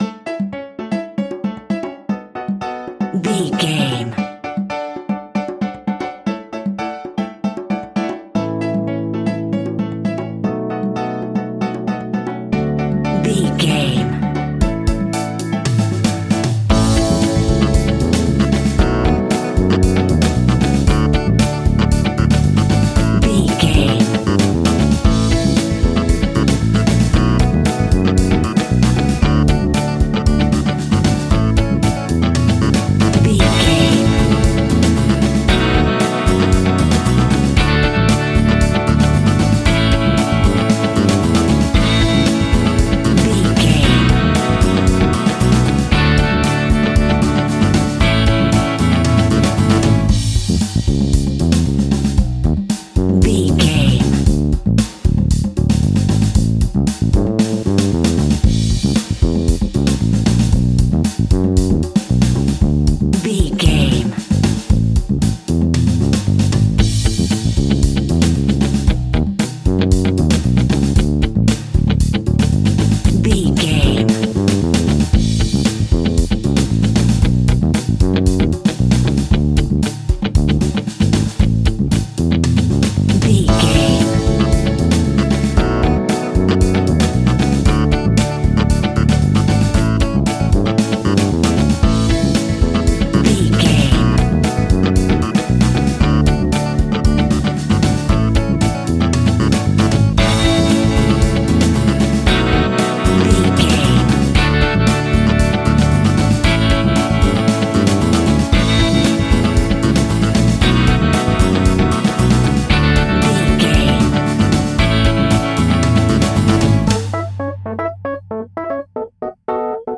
Play Pop Music Theme.
Ionian/Major
electro pop
pop rock
pop rock instrumentals
happy
upbeat
bouncy
drums
bass guitar
electric guitar
keyboards
hammond organ
acoustic guitar
percussion